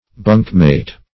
\bunk"mate`\
bunkmate.mp3